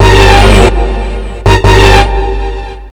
09 End Stop 165 Bb.wav